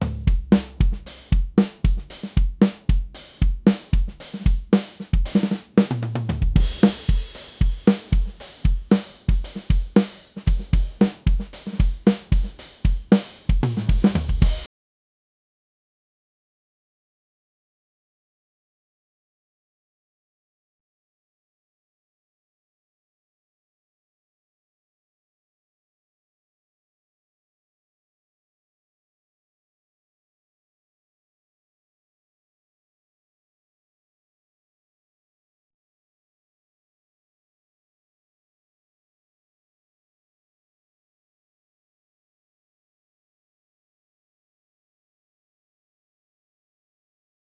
A model that transforms rhythmic audio inputs into professional drum performances by applying a multi-stage generative process, producing four unique variations per input.
• Converts diverse rhythmic sources (drums, beatboxing, body percussion) into studio-quality drum tracks